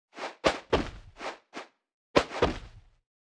Media:anim_fast_food_tank_01.wav 动作音效 anim 查看其技能时触发动作的音效
Anim_fast_food_tank_01.wav